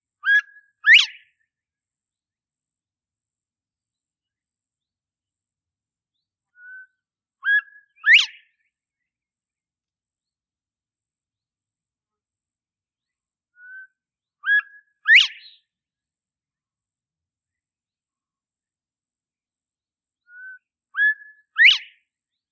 Northern Bobwhite
Northern Bobwhite - Song
Loud, whistled bob-white!.